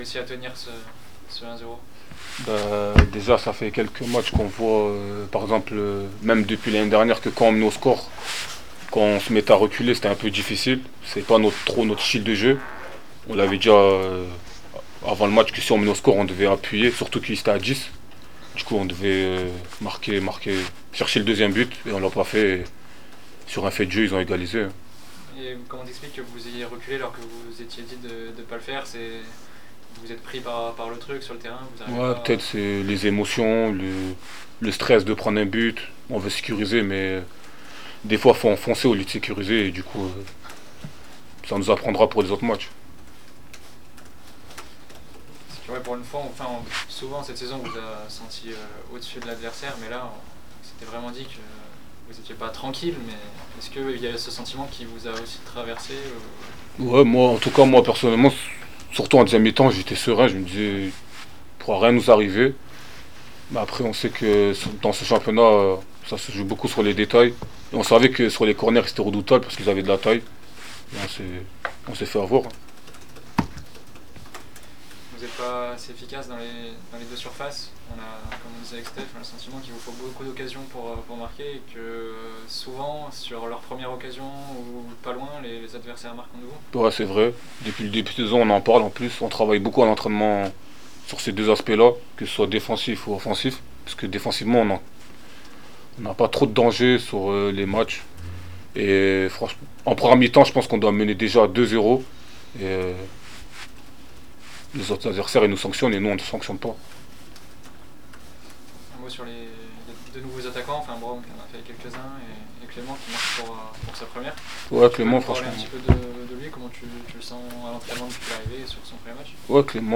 27 septembre 2025   1 - Sport, 1 - Vos interviews